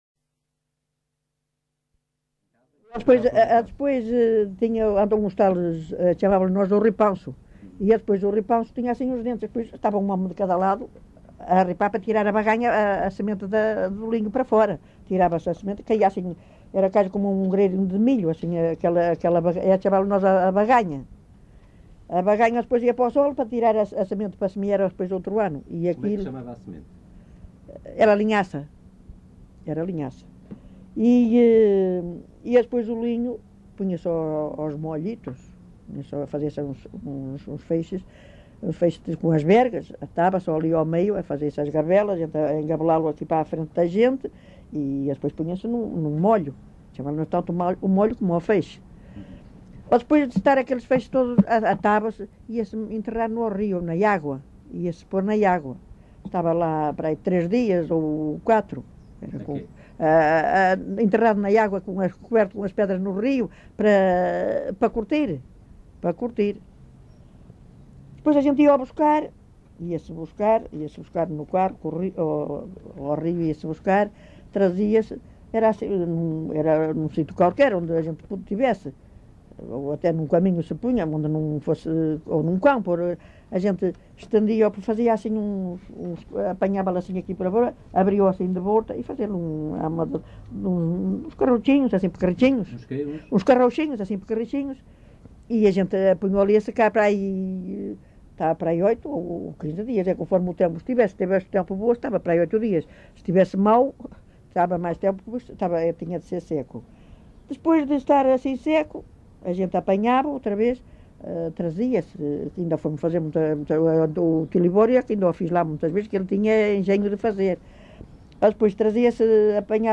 LocalidadeArcos de Valdevez (Arcos de Valdevez, Viana do Castelo)